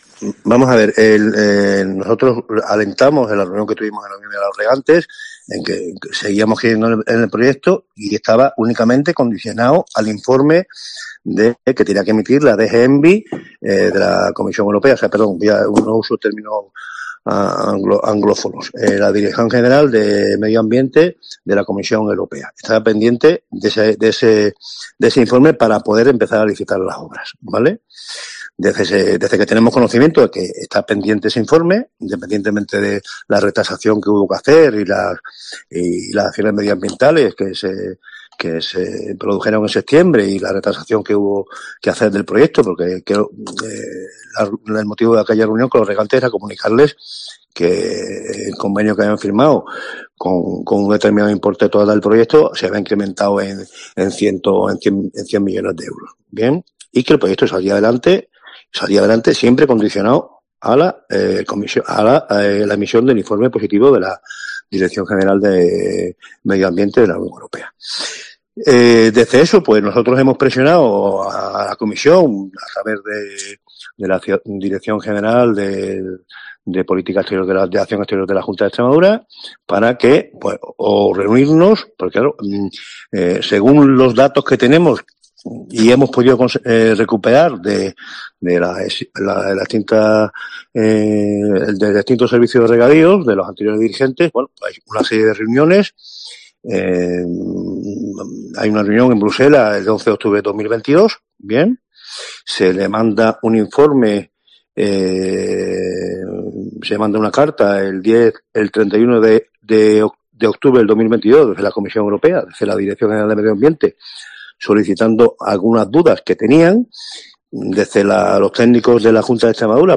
AUDIO: En COPE hemos hablado con el director general de Infraestructuras Agrarias de la Junta, José María Sánchez Cordero, para analizar...